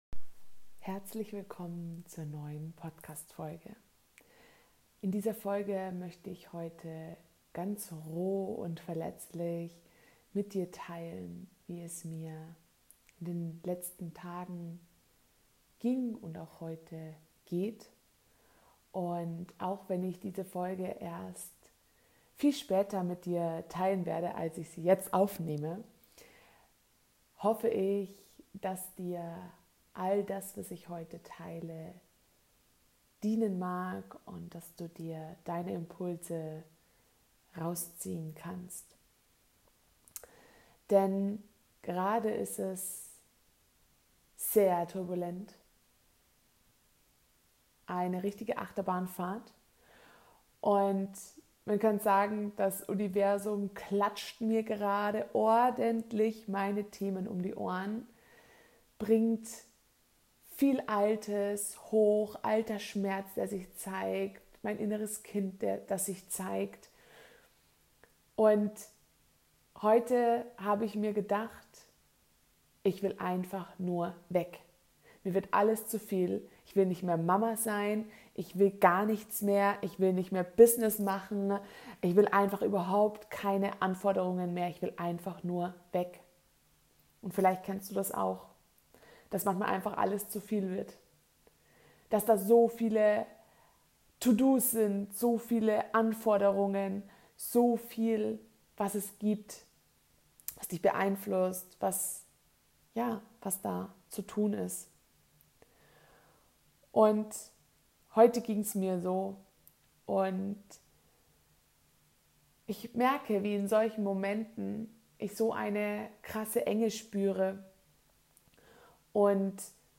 Aber diese Folge ist aus und in einem so kraftvollen Moment entstanden, dass ich wusste, auch wenn ich noch nicht weiß wann, möchte ich genau das teilen (und habe damals einfach in meine Sprachnotizen gesprochen). Diese Folge ist eine Mischung aus einem ehrlichen Teilen wie gefangen ich mich in mir selbst gefühlt habe, wie ich mich dann aus diesem „Wegwollen“ und der inneren Enge rausgeholt habe, einem kraftvollen Power Talk für Dich sowie eine Einladung was Du tun kannst, wenn es Dir auch so geht und Du es selbst nicht herausschaffst.